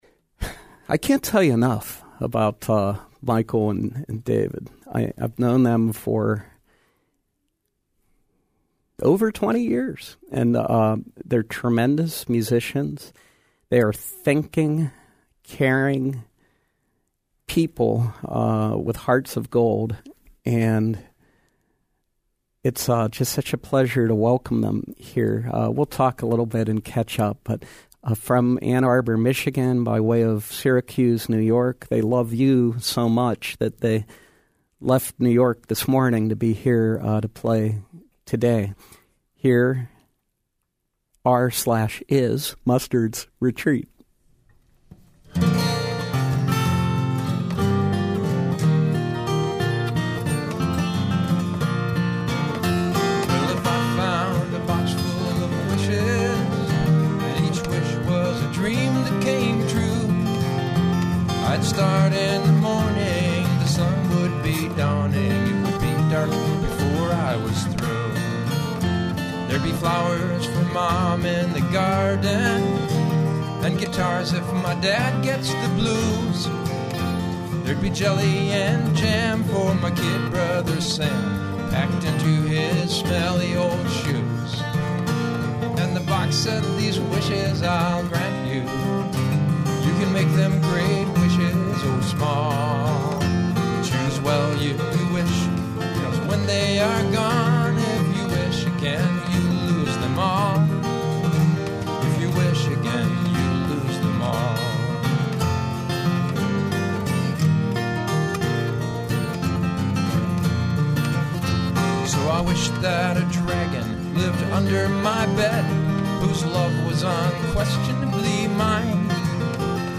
We’re pleased to welcome the wonderful Ann Arbor folk duo
live in our studios.